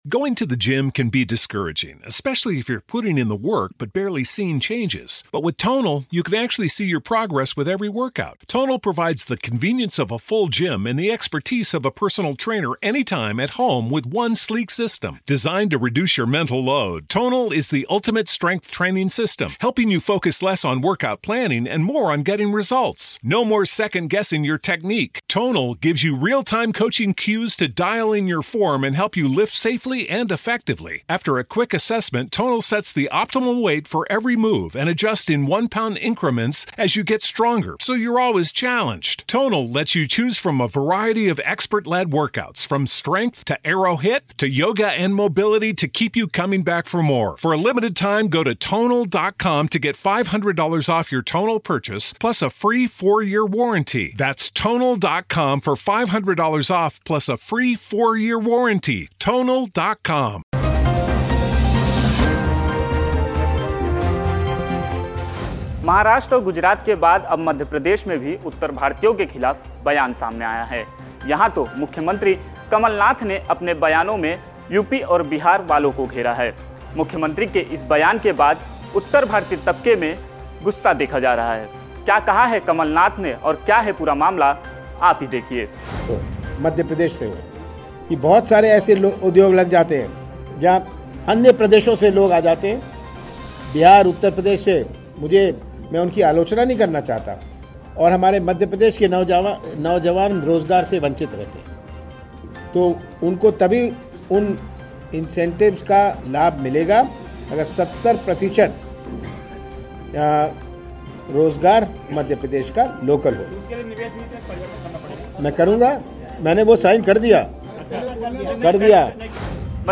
न्यूज़ रिपोर्ट - News Report Hindi / शिवसेना और राज ठाकरे की राह पर चले कमलनाथ ?